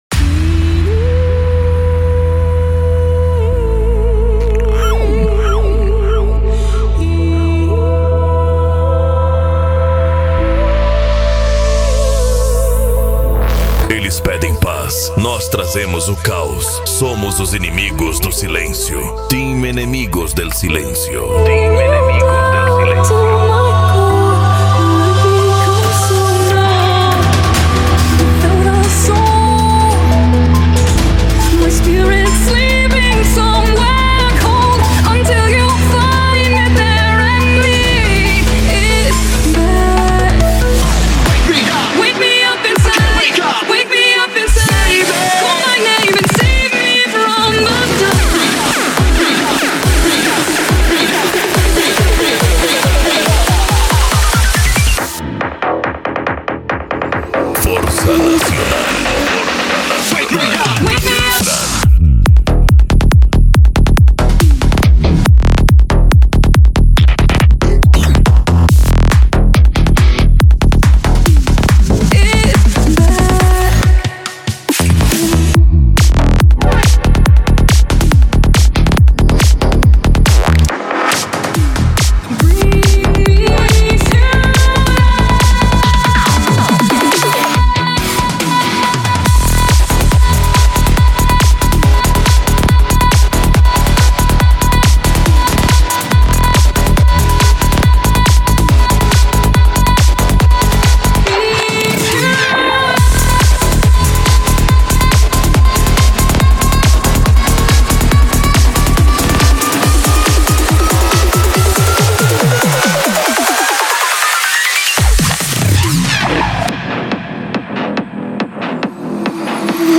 Bass
Mega Funk
Remix